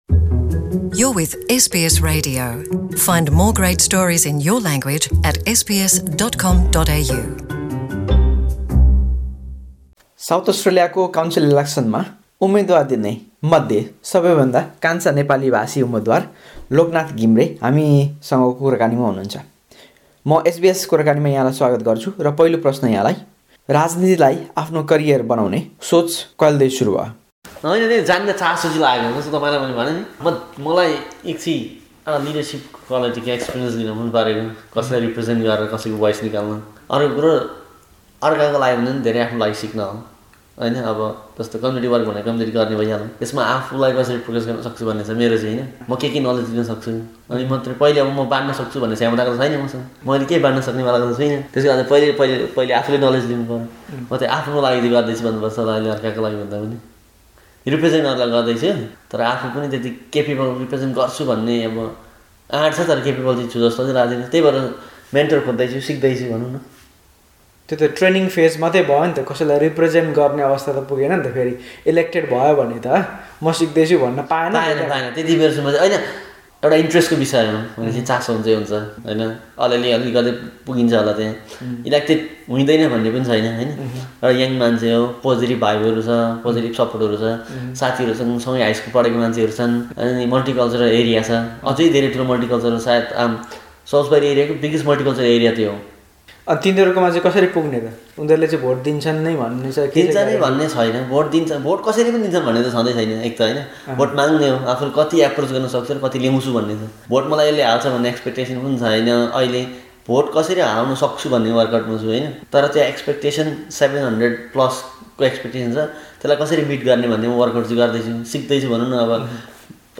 गर्नुभएको कुराकानी